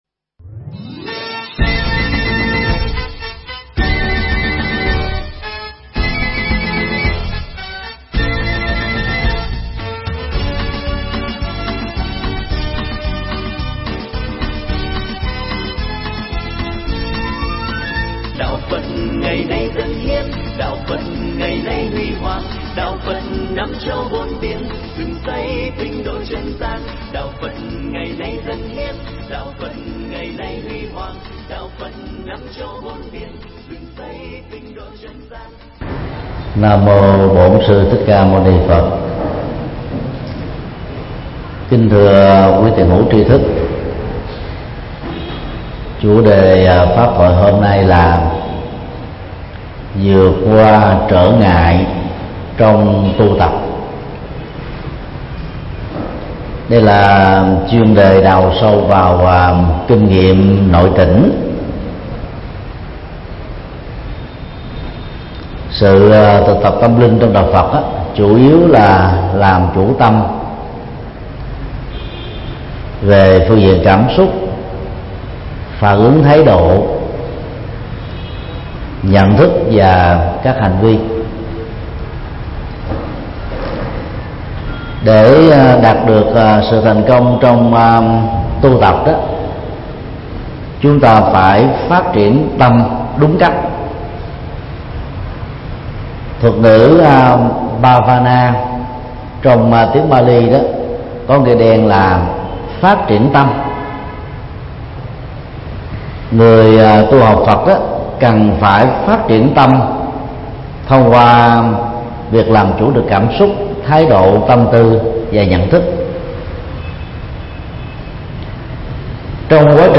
Mp3 Pháp Thoại Vượt qua trở ngại trong tu tập - Thầy Thích Nhật Từ Giảng tại chùa Giác Ngộ 92 Nguyễn Chí Thanh, Phường 3, Quận 10, ngày 26 tháng 4 năm 2015